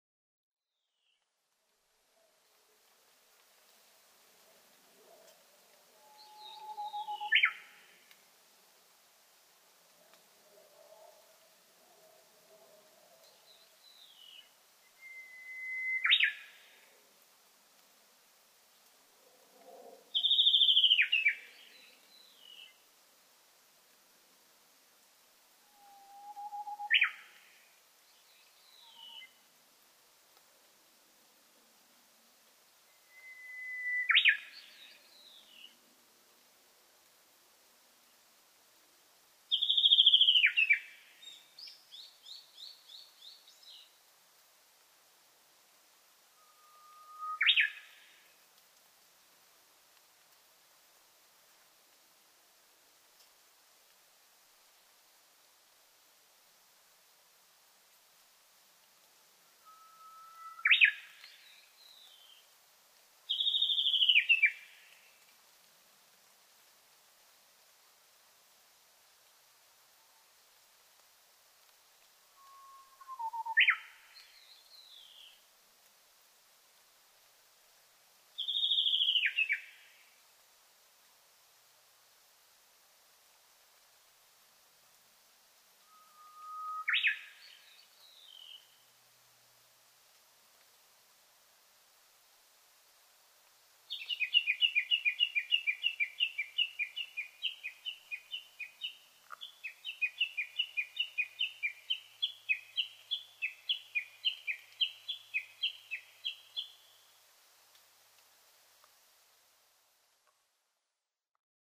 ウグイス　Cettia diphoneウグイス科
三重県いなべ市　alt=700m
Mic: built-in Mic.
「谷渡り」が変わっている
他の自然音：ヒヨドリ